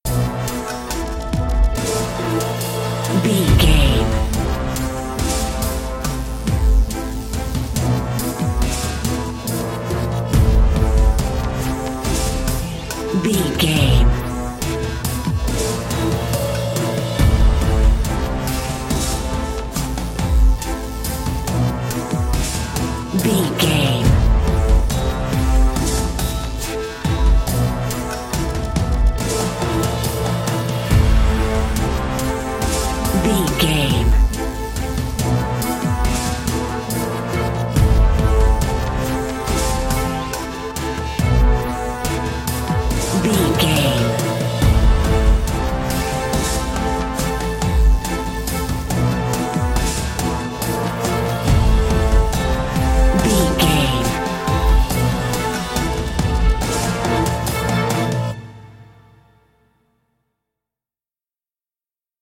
Dorian
strings
drum machine
synthesiser
orchestral hybrid
dubstep
aggressive
energetic
intense
synth effects
wobbles
heroic
driving drum beat
epic